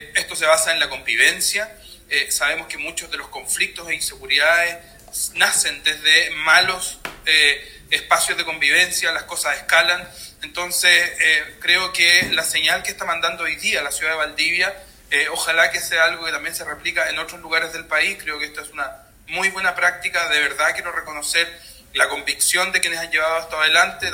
Convivencia-Fluvial-1-Subsec-Prevención-del-Delito-Eduardo-Vergara.m4a